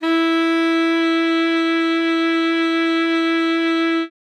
42c-sax06-e4.wav